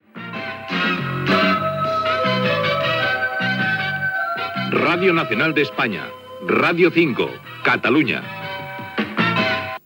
Indicatiu de l 'emissora